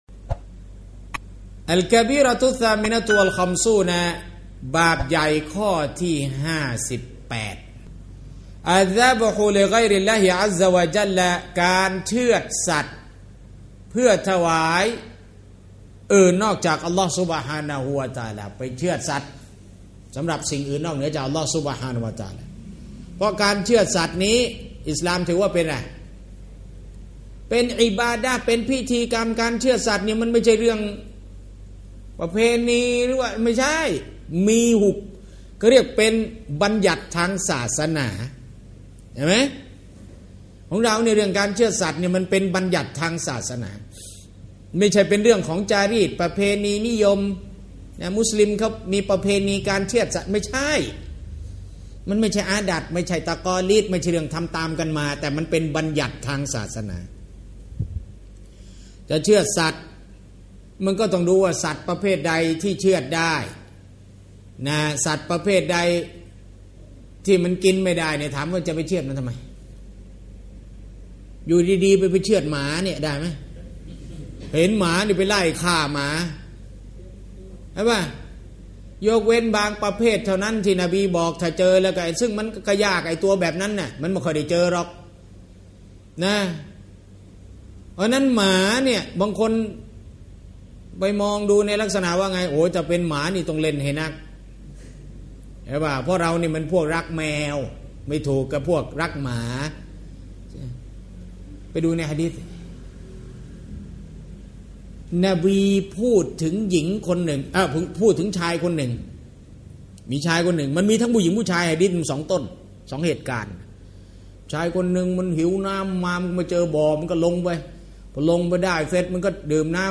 สถานที่ : บาแลอันนูร ซอยสะและมัด